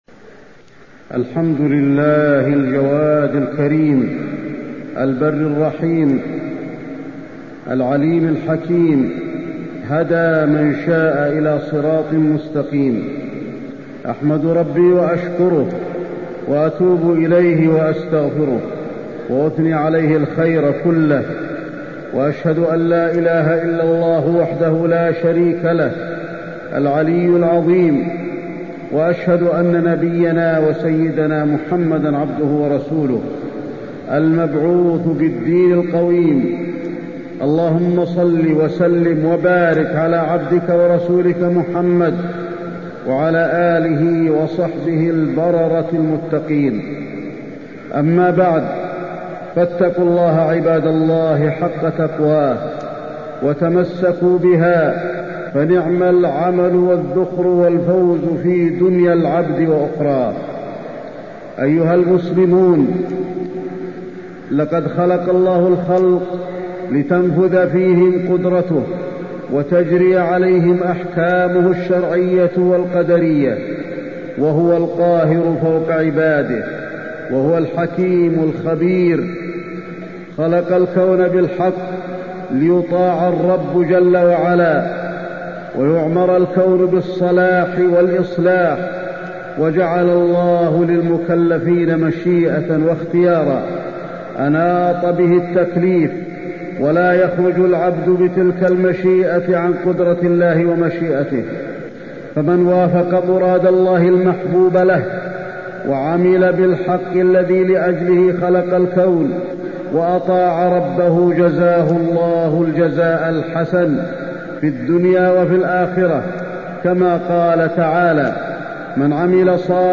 تاريخ النشر ٢١ جمادى الأولى ١٤٢٥ هـ المكان: المسجد النبوي الشيخ: فضيلة الشيخ د. علي بن عبدالرحمن الحذيفي فضيلة الشيخ د. علي بن عبدالرحمن الحذيفي أحوال الجنة والنار